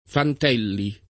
[ fant $ lli ]